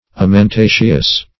Amentaceous \Am`en*ta"ceous\, a. [LL. amentaceus.] (Bot.)